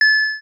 Pickup.m4a